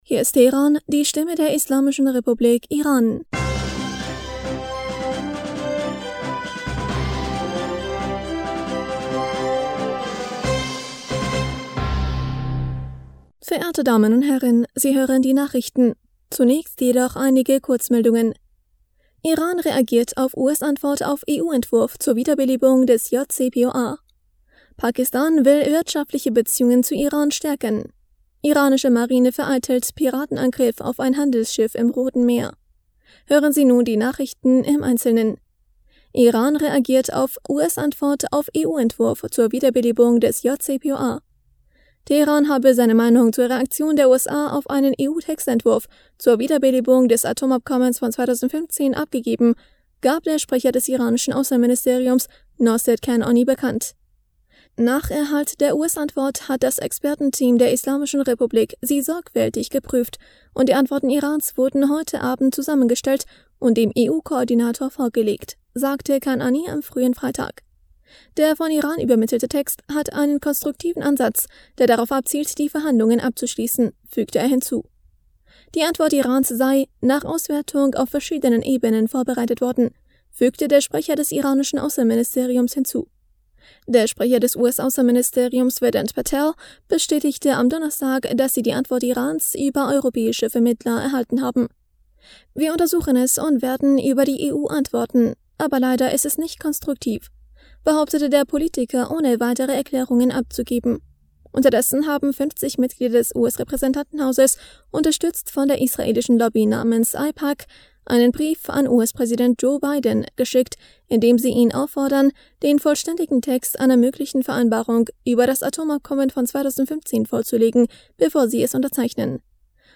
Nachrichten vom 2. September 2022
Die Nachrichten von Freitag, dem 2. September 2022